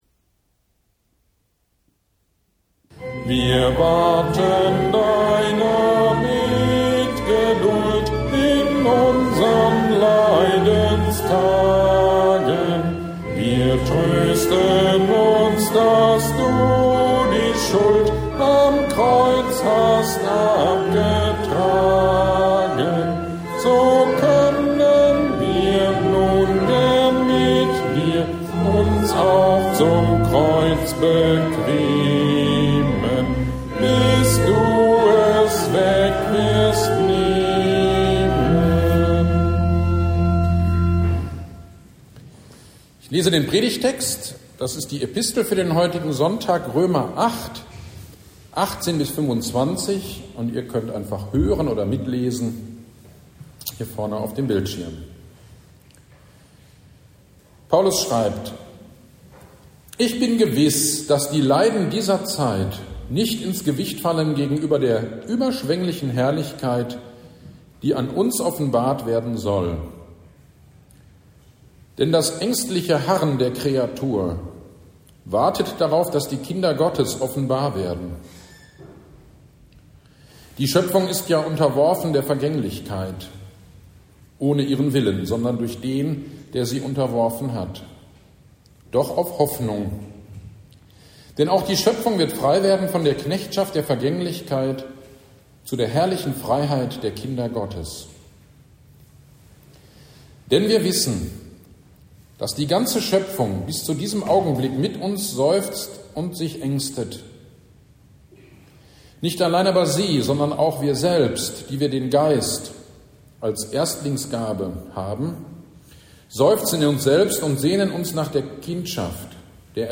GD am 12.11.2023 Predigt zu Römer 8.18-25 - Kirchgemeinde Pölzig